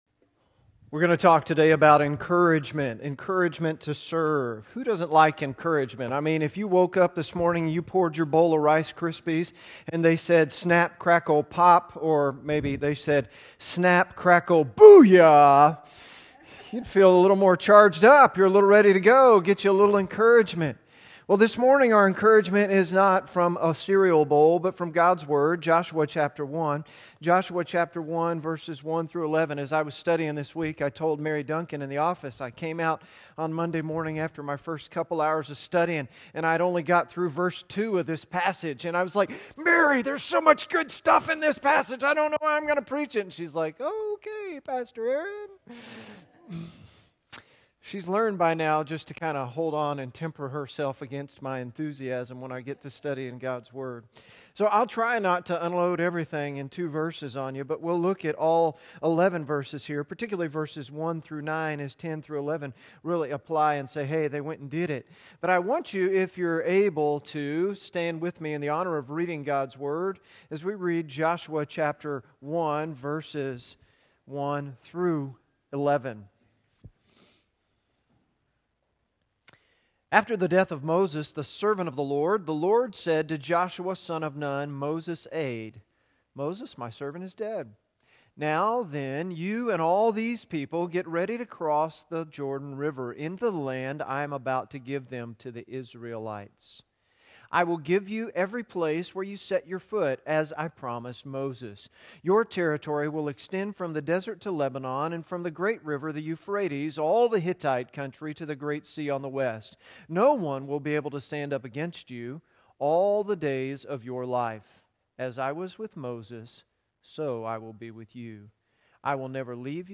Minister to Others — Southview Sermons — Southview Baptist Church